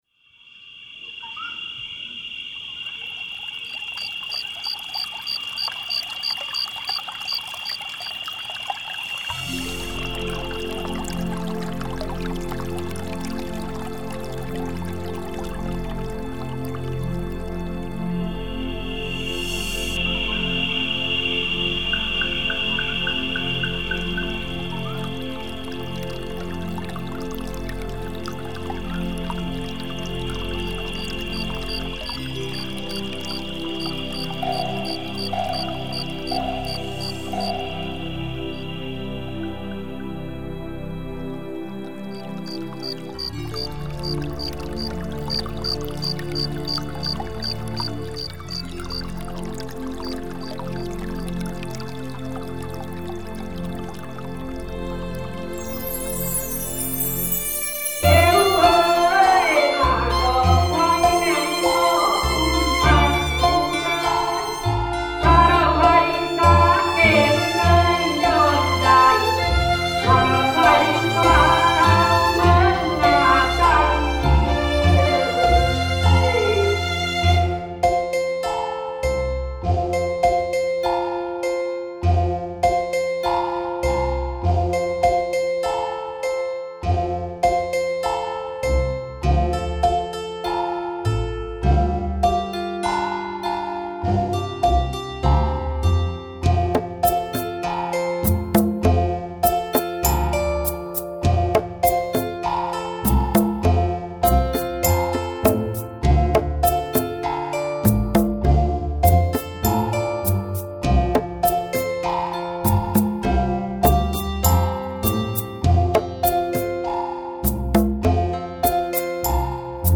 调式 : F